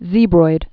(zēbroid)